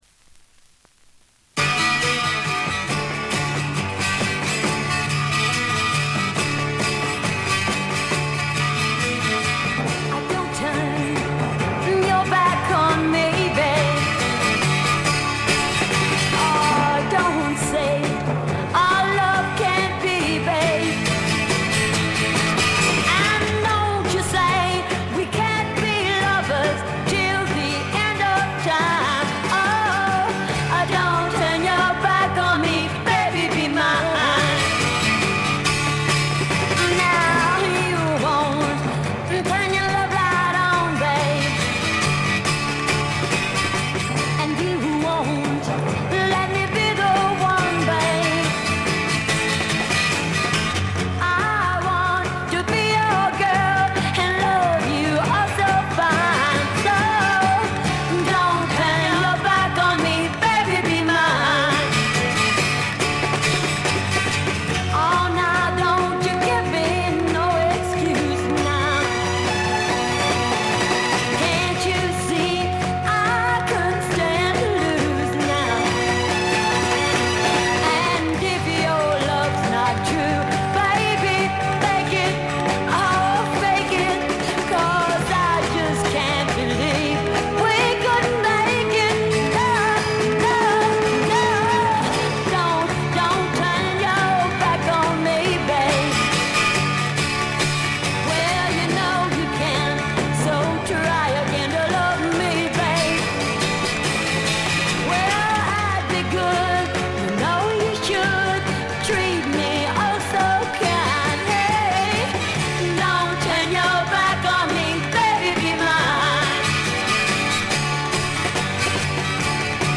存在感ありまくりのヴォーカルが素晴らしいです。
試聴曲は現品からの取り込み音源です。